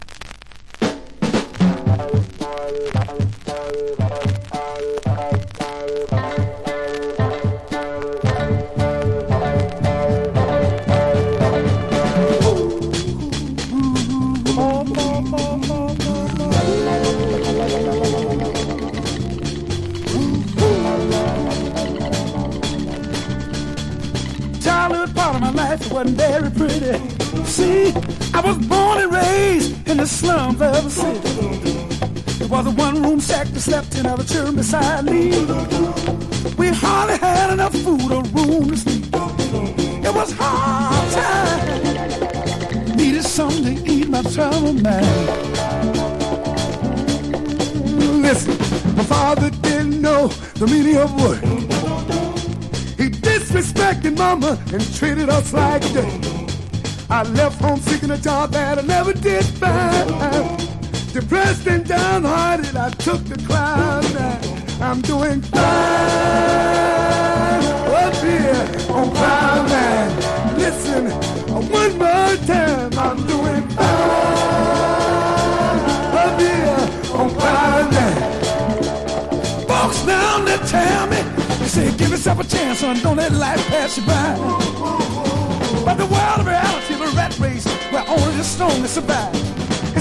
FUNK / DEEP FUNK
所によりノイズありますが、リスニング用としては問題く、中古盤として標準的なコンディション。